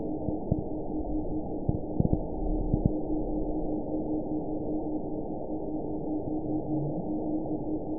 event 921957 date 12/23/24 time 08:54:43 GMT (4 months, 2 weeks ago) score 9.18 location TSS-AB04 detected by nrw target species NRW annotations +NRW Spectrogram: Frequency (kHz) vs. Time (s) audio not available .wav